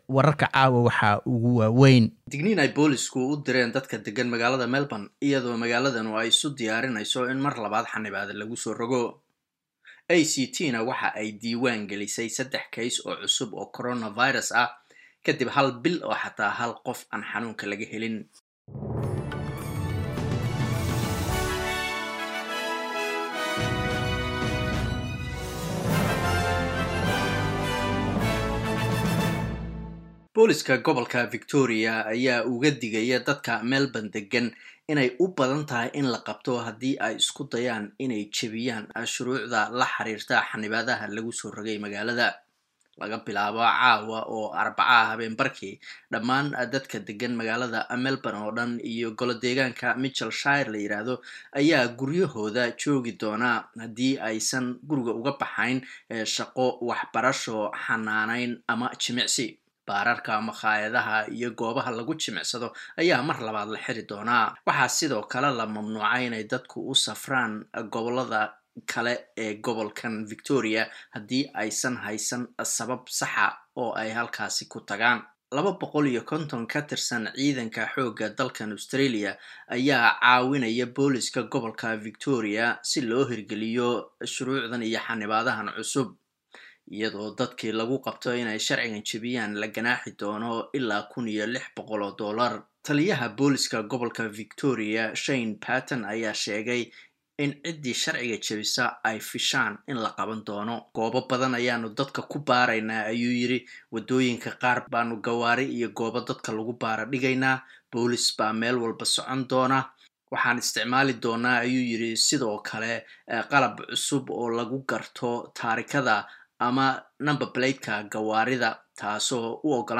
Wararka SBS Somali Arbaco 08 July